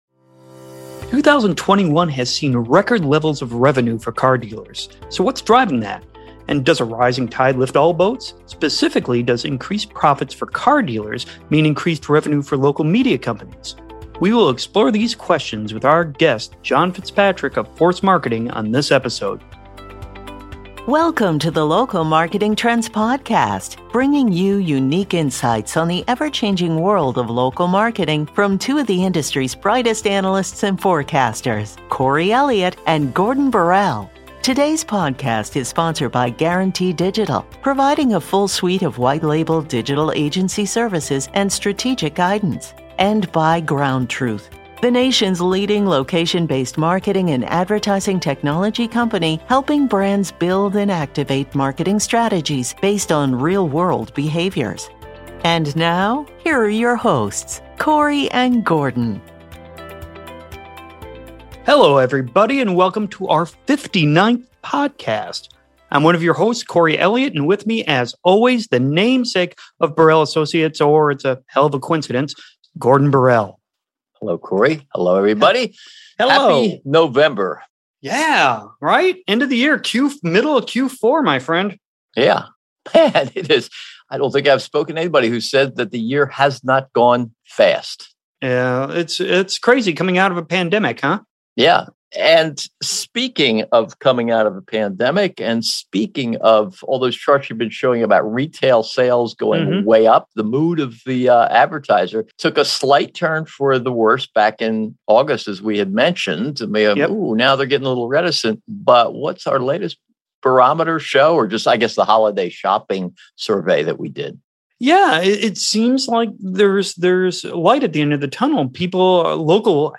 interviewing leading car-marketing expert